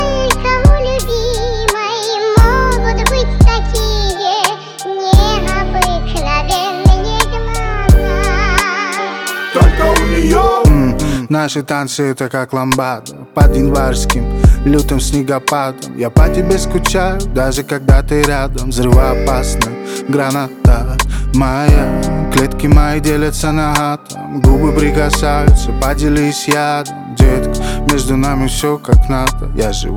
Pop Alternative Indie Pop